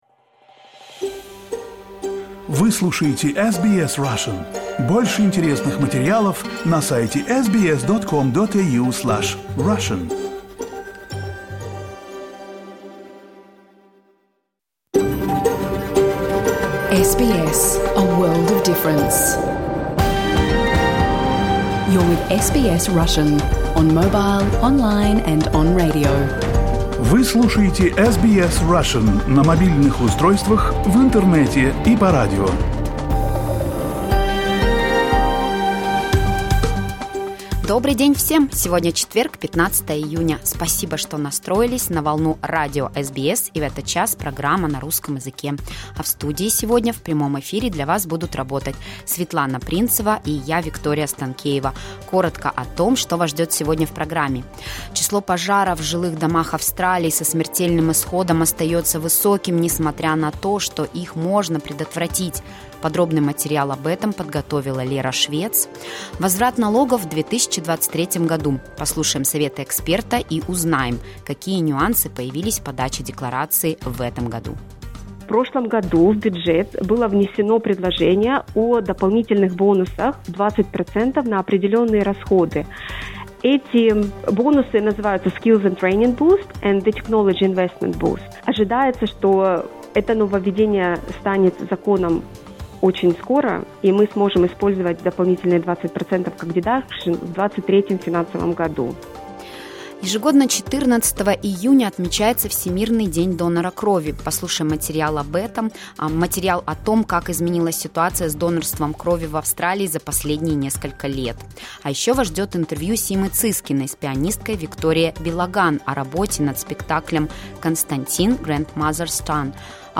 You can listen to SBS Russian program live on the radio, on our website and on the SBS Audio app.